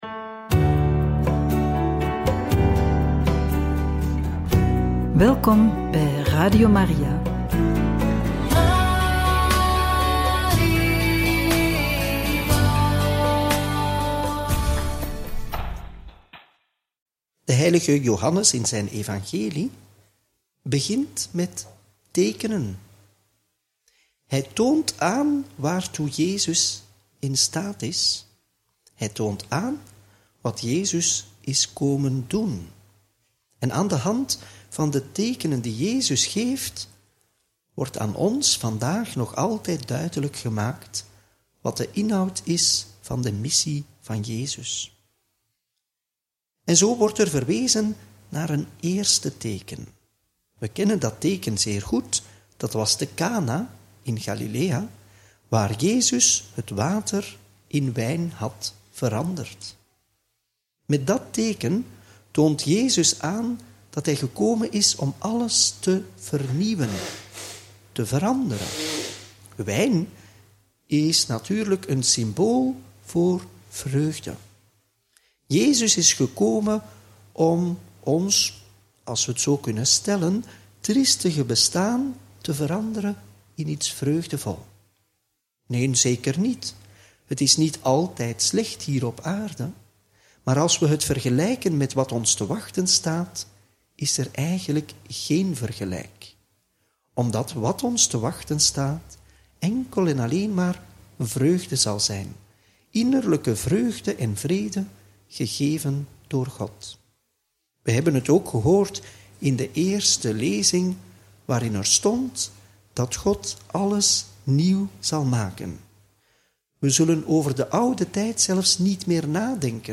Homilie bij het Evangelie van maandag 31 maart 2025 – Joh. 4, 43-54